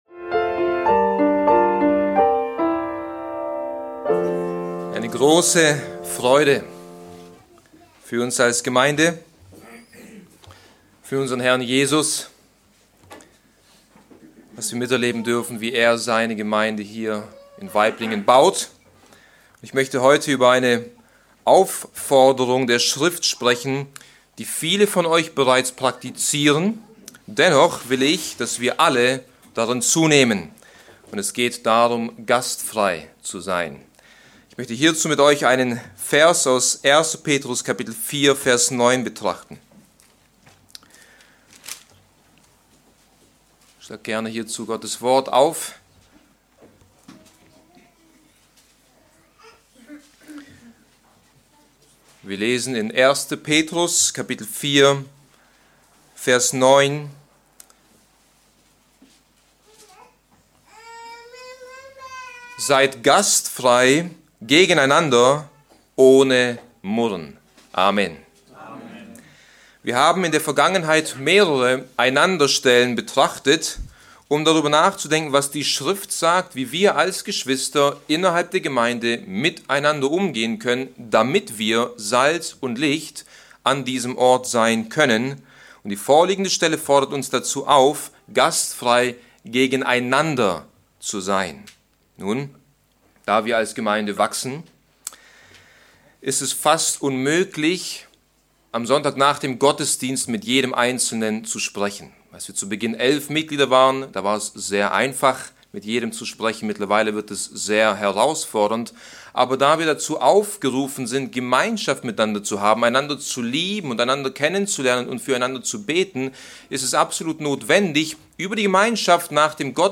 Allein aus Gnade - Bibeltreue Predigten der Evangelisch-Baptistischen Christusgemeinde Podcast